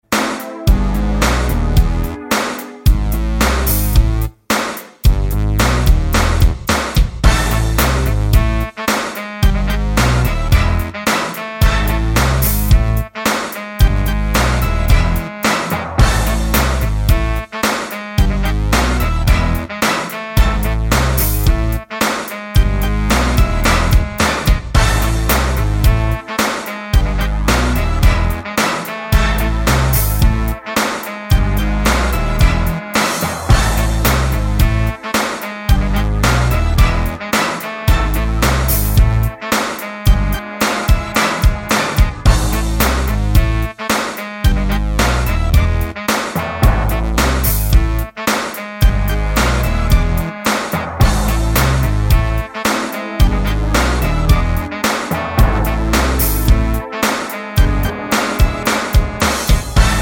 no Backing Vocals R'n'B / Hip Hop 4:49 Buy £1.50